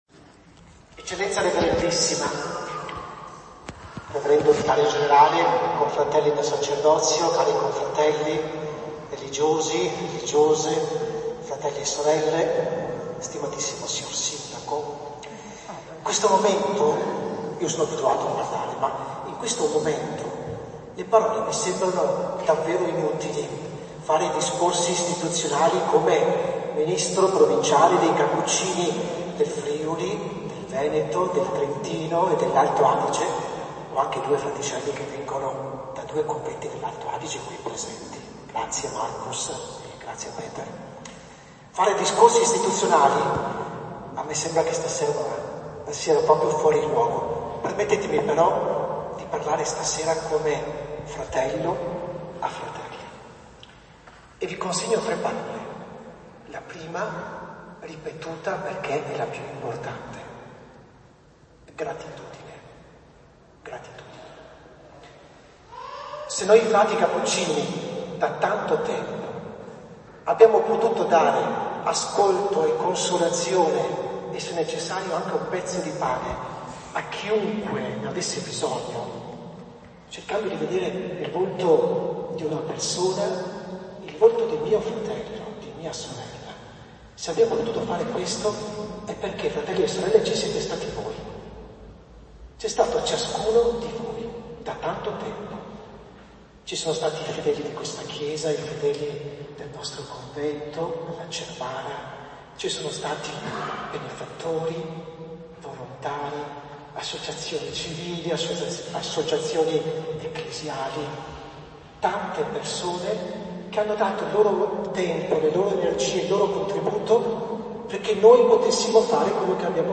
In una Badia di San Lorenzo affollata di fedeli, Trento ha salutato nella serata di domenica 17 settembre la comunità dei frati Cappuccini che lasciano definitivamente il capoluogo dopo quasi 500 anni di presenza.